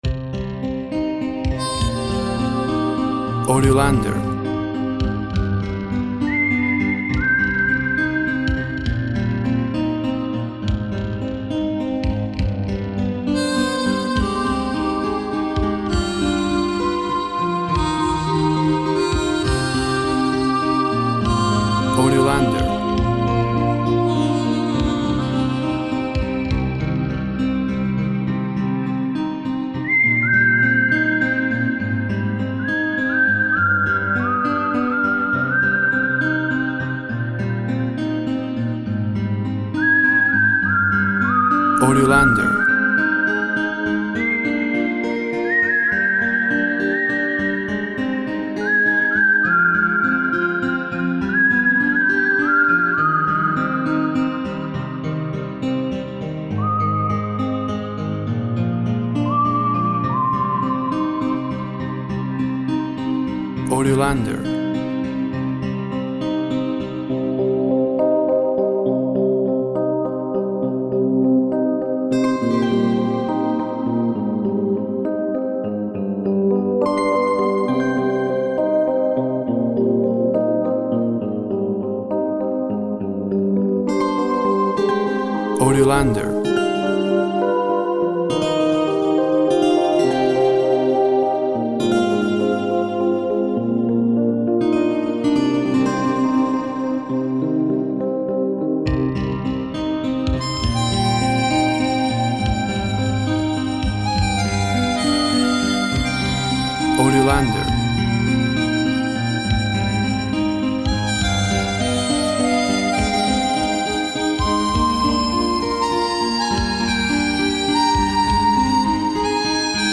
Music noire in swing style.
Tempo (BPM) 65